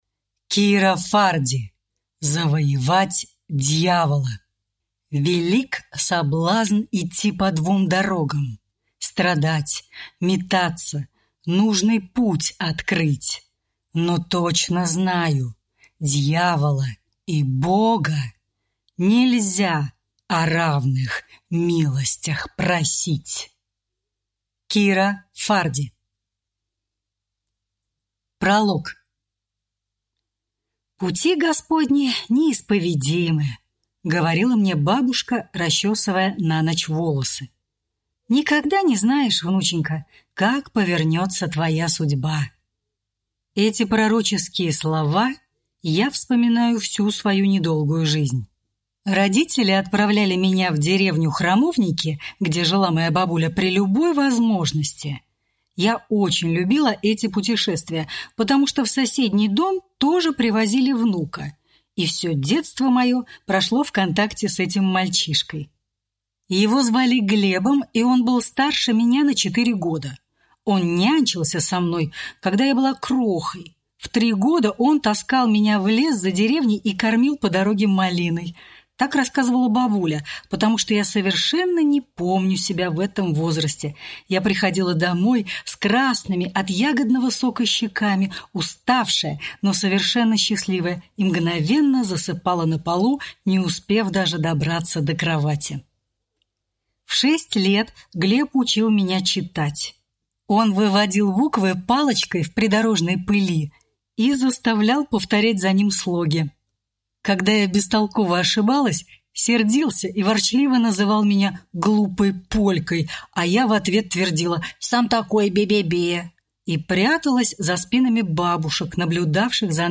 Aудиокнига Завоевать дьявола